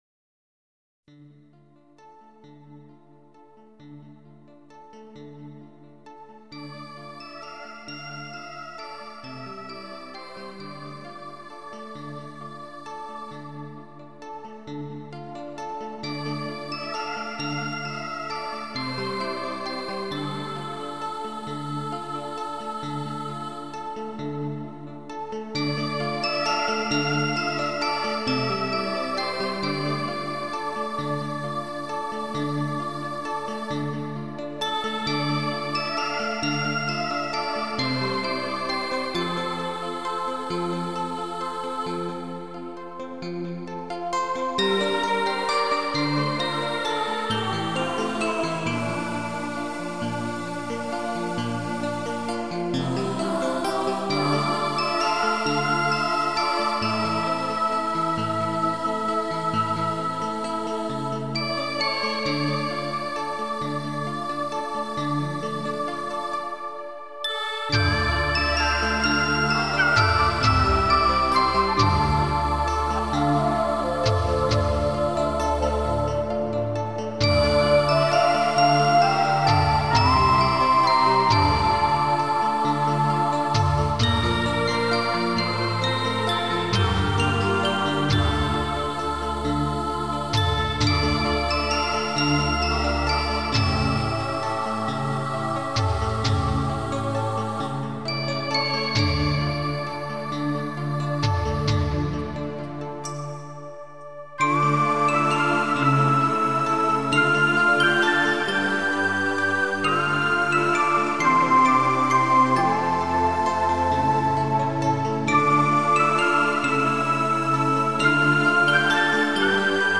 专辑类别：录音室专辑
专辑风格：新世纪音乐 New Age